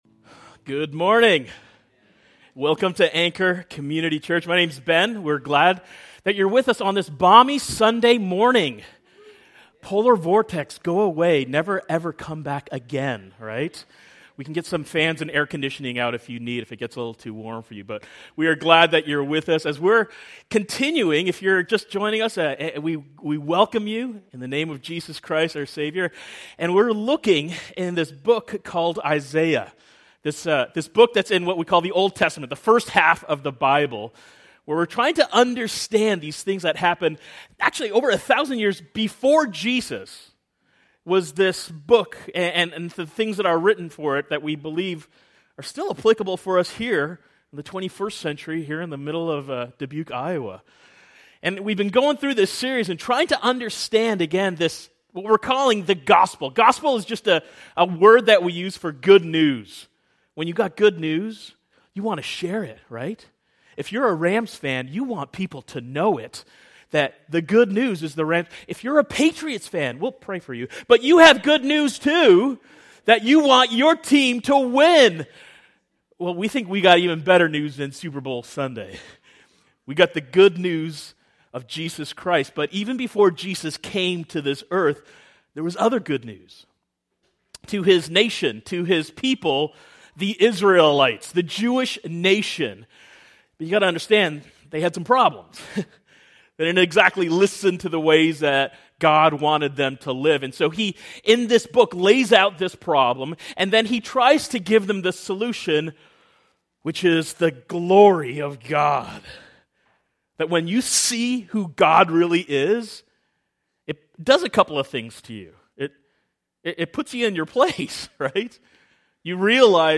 Sermons | Anchor Community Church